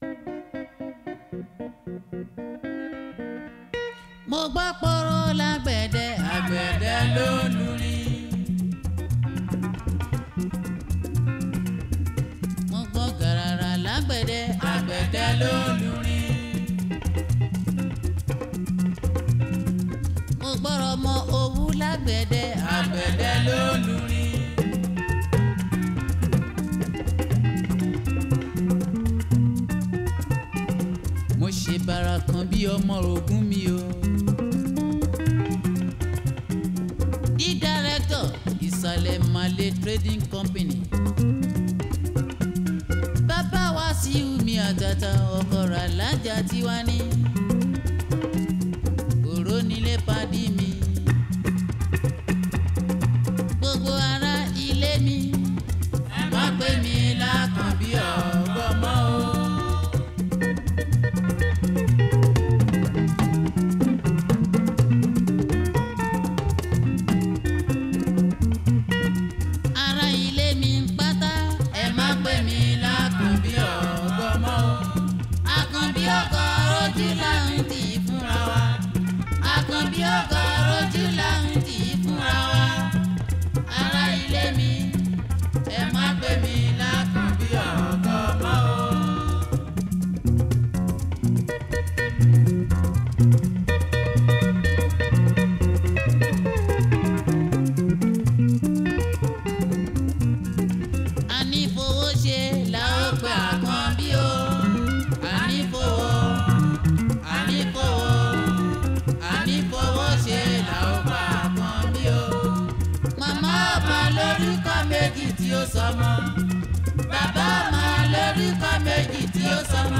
Gospel
is a Nigerian jùjú singer